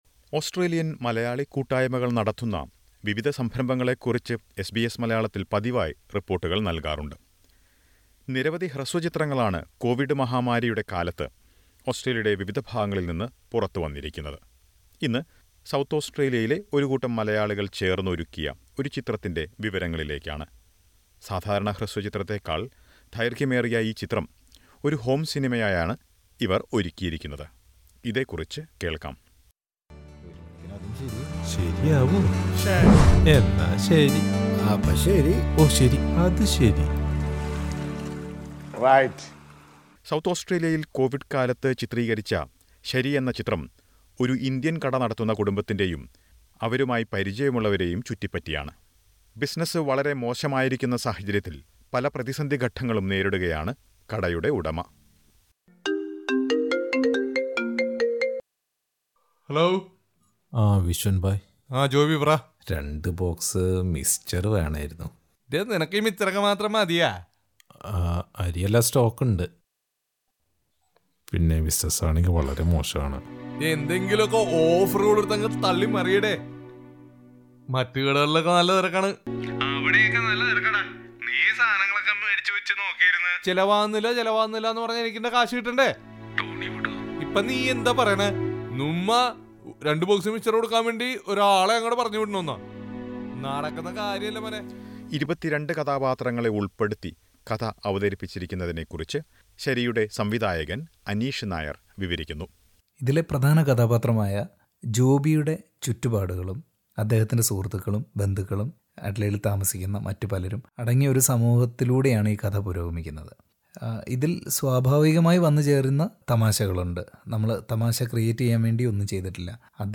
A Covid time Malayalam Home Cinema from Adelaide has been well received in Kerala as well. Listen to a report about the movie 'Shari'.